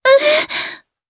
Yuria-Vox_Damage_jp_02.wav